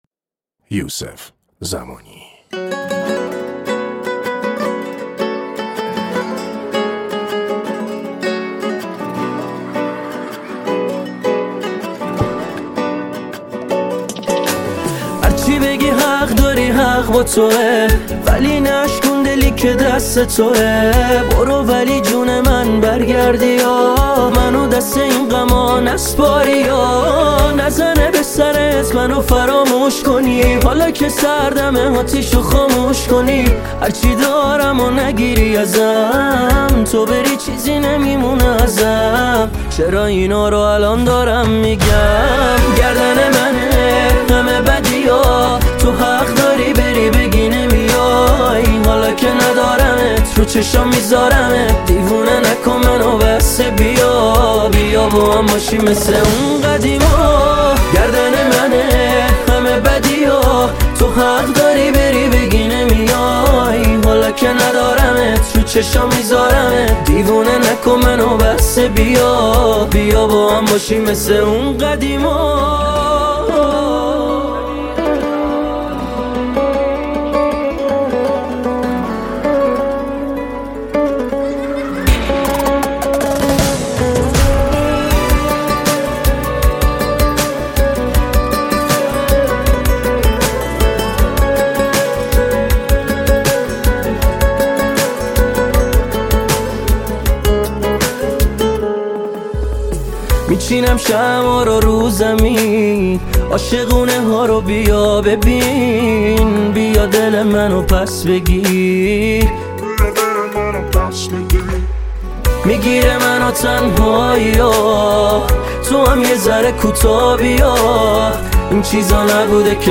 • دسته آهنگ پاپ